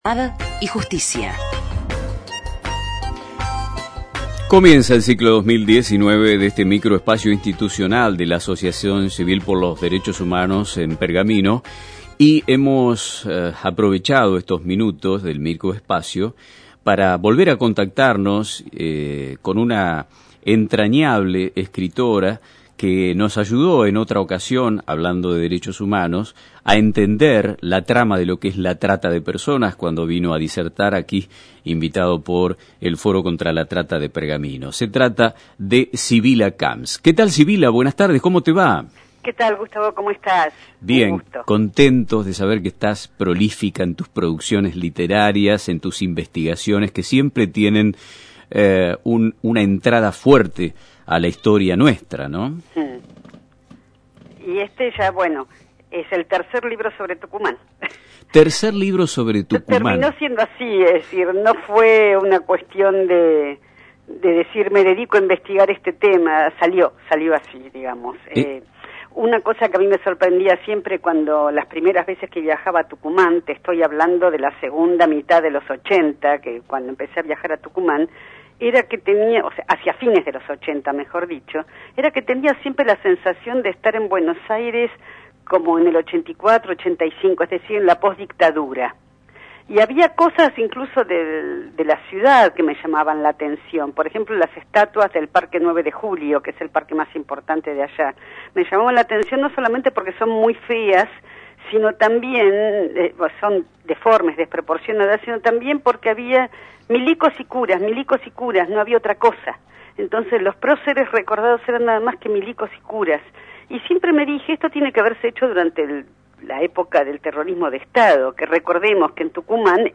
Radio-Mon-de-Pergamino.mp3